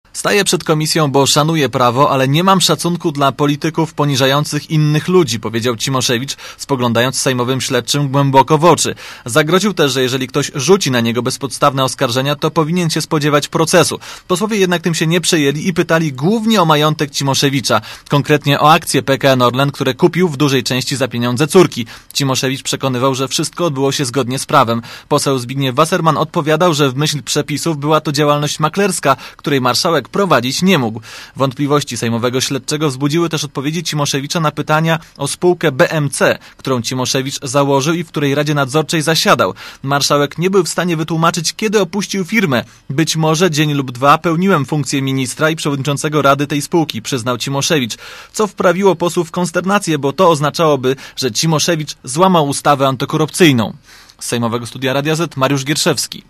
Relacja
cimoszewicz-przed_komisja.mp3